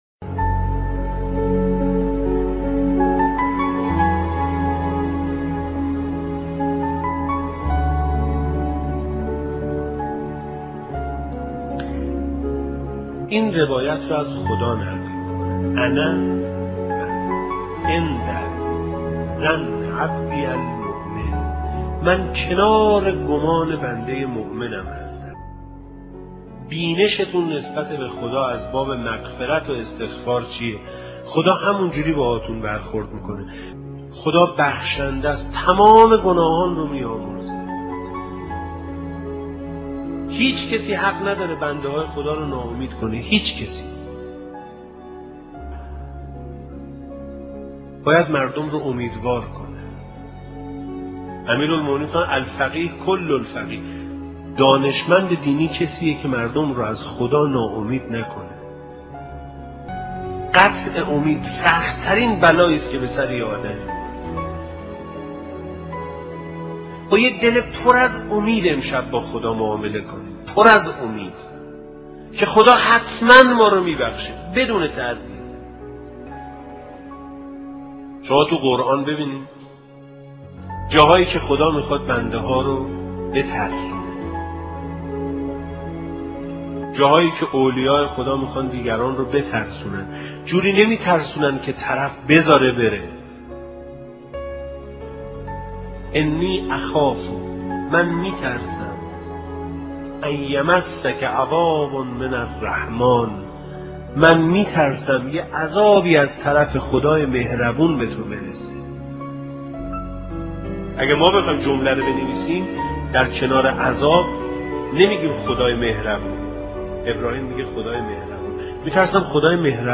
صوت سخنرانی حجت الاسلام و المسلمین